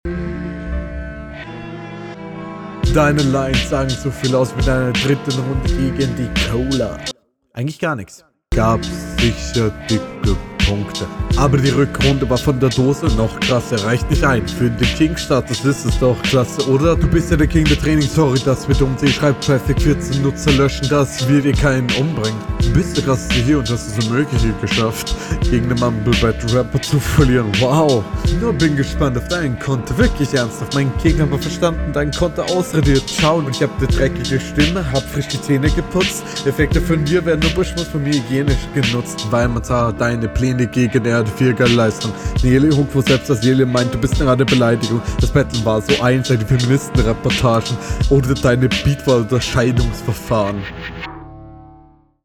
Stimmlich fand ich es schwächer als runde 1, deutlicher abstieg.
Einschläfender, schiefer Beat naja Geschmäcker sind verschieden und ist …